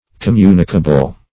Communicable \Com*mu"ni*ca*ble\ (k[o^]m*m[=u]"n[i^]*k[.a]*b'l),